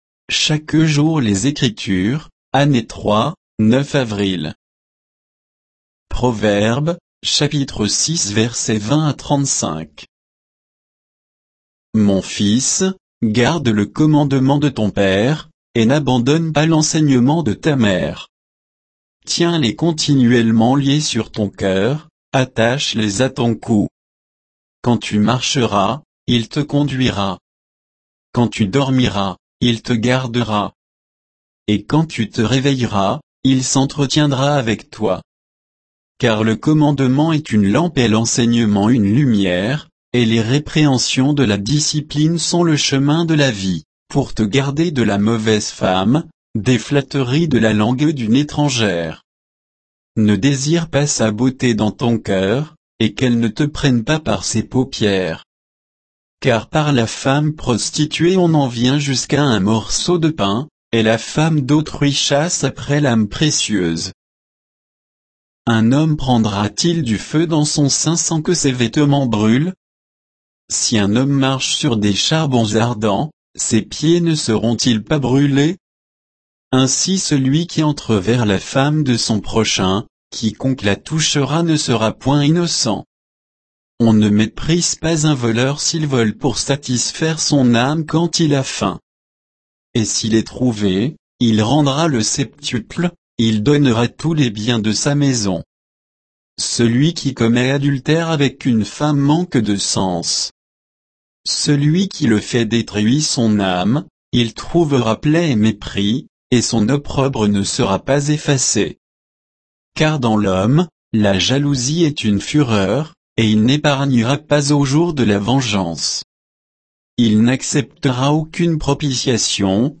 Méditation quoditienne de Chaque jour les Écritures sur Proverbes 6